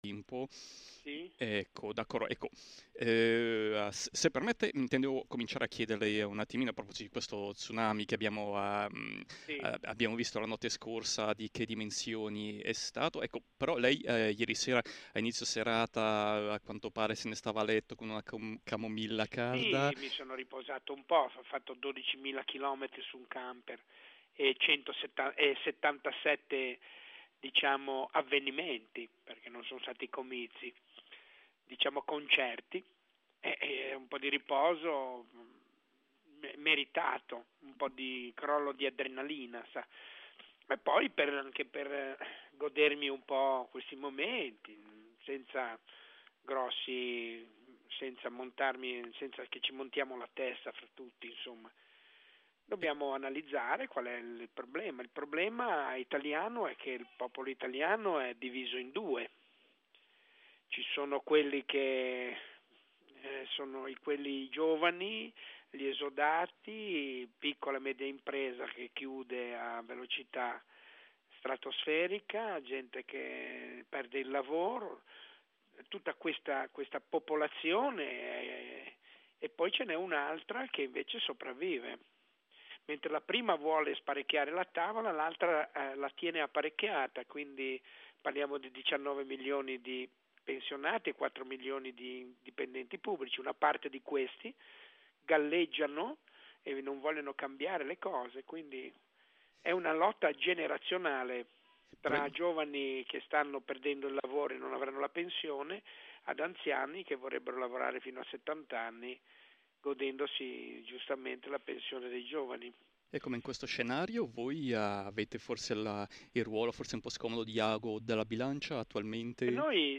Il comico genovese non solo ha confermato il suo rifiuto di allearsi a una coalizione, ma ha evocato anche la possibilità di tornare alle urne. L’intervista integrale ed esclusiva è stata pubblicata sul sito della RSI il 26 febbraio 2013.